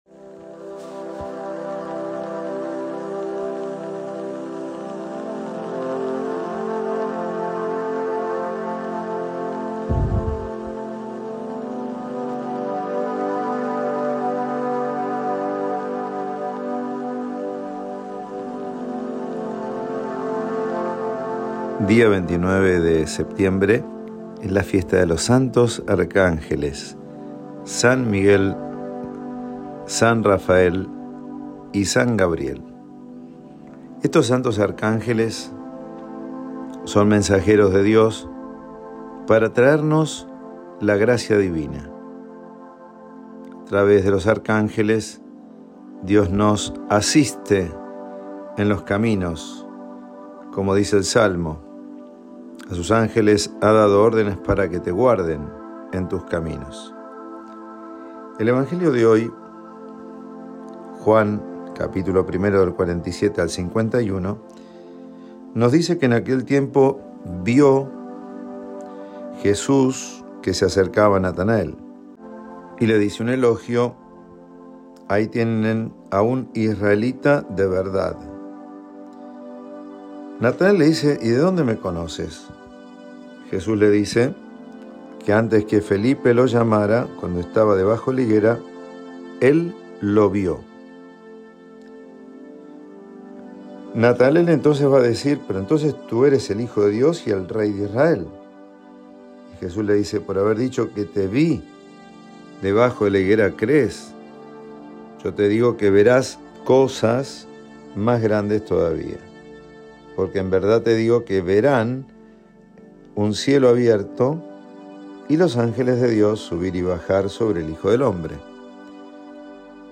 Homilía Evangelio según san Juan 1, 47-51 Santos Arcángeles Miguel